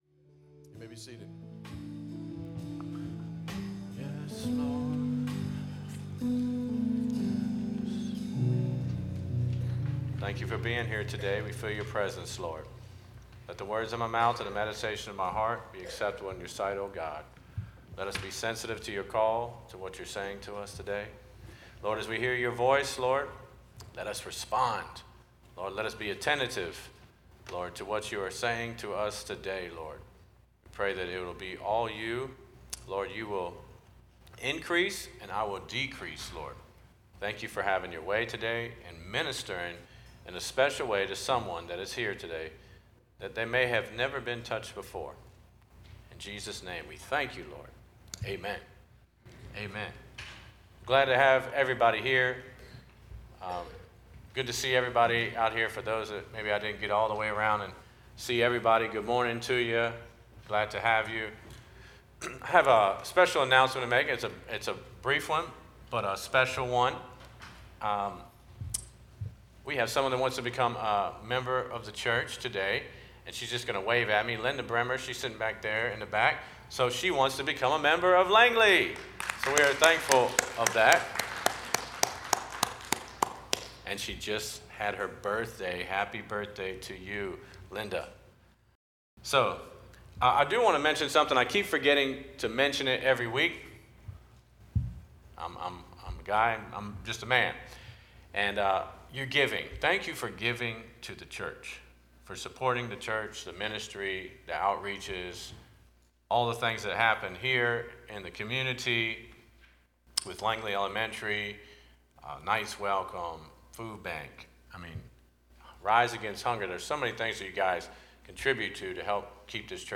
11/19/23 Sermon